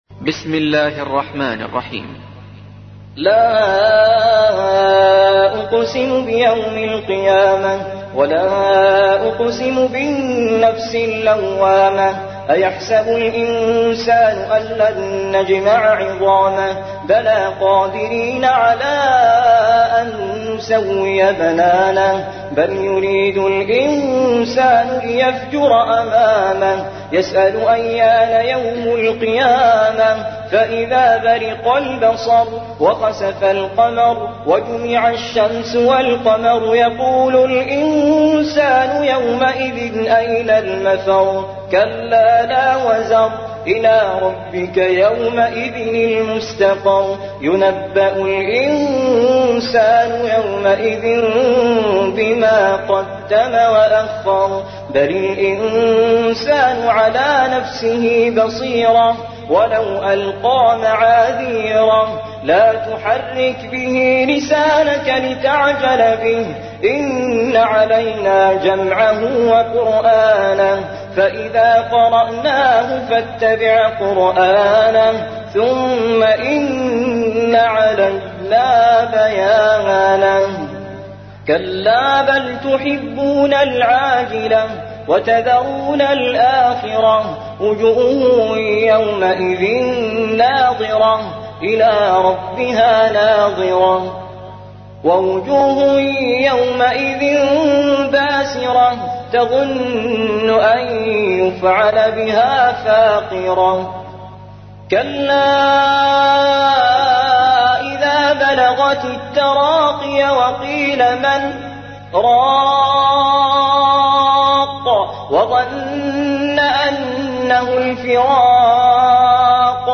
75. سورة القيامة / القارئ